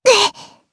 Lewsia_A-Vox_Damage_jp_01.wav